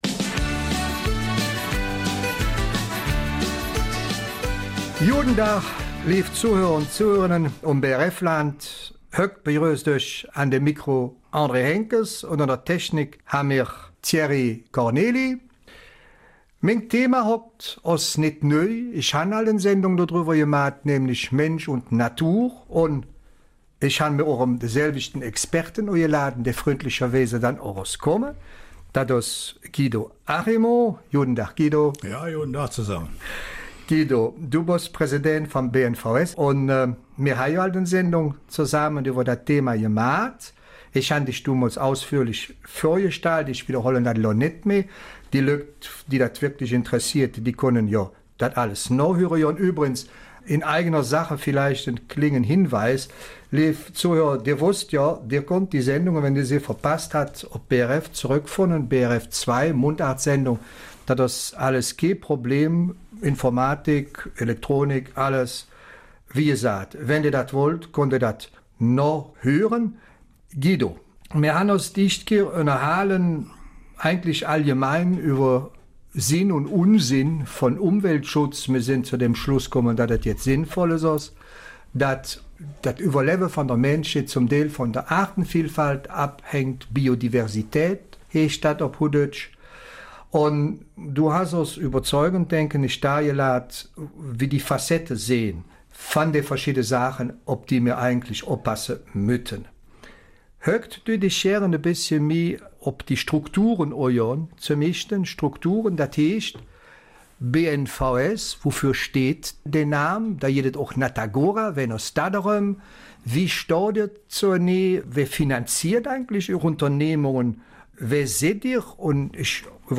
Eifeler Mundart: Mensch und Natur - eine Lebensgemeinschaft